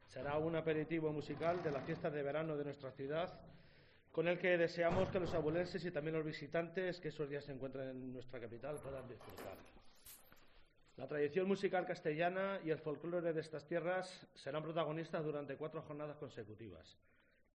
Declaraciones Ángel Sánchez concejal de cultura sobre el festival de folk